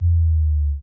bass1.ogg